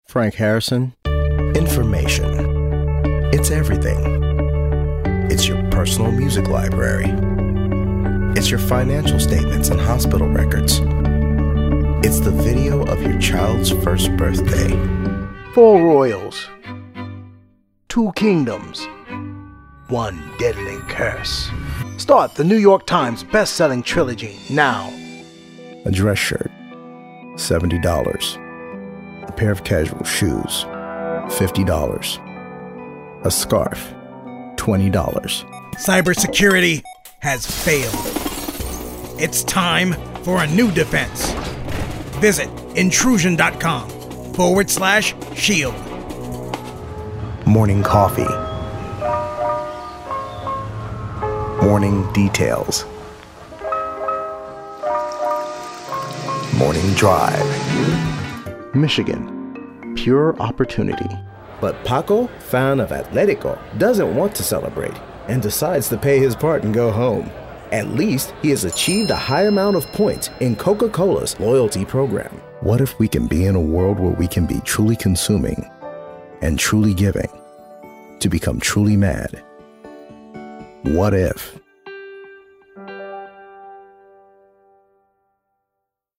Commercials 0:00 / 0:00
Acoustically treated home studio for pristine audio recordings.
Commercials.mp3